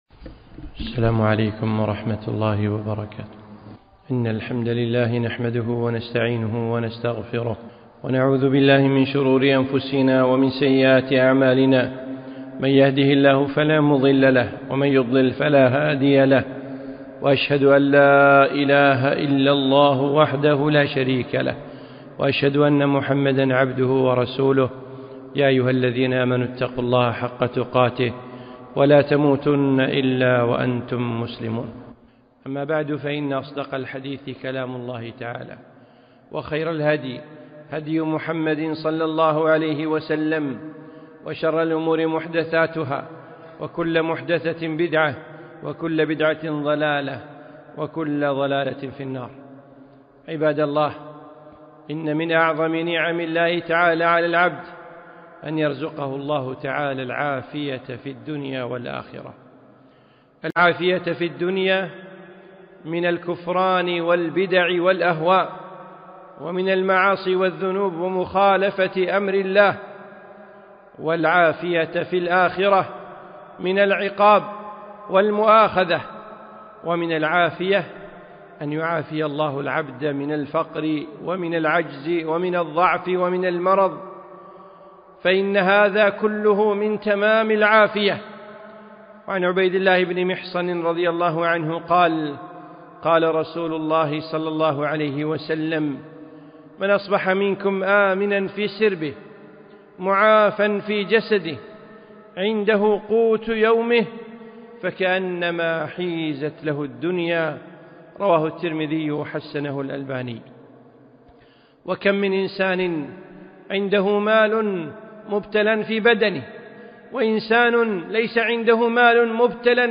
خطبة - العافية